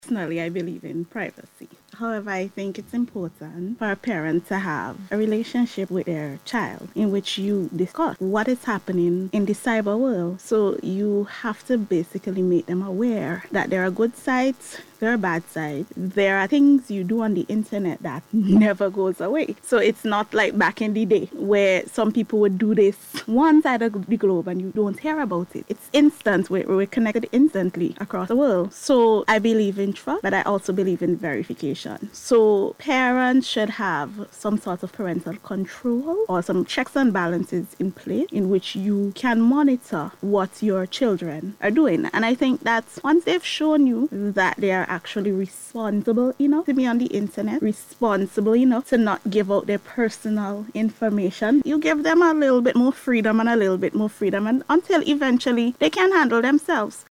issued the advice while speaking on the On Beat Programme on NBC Radio last evening.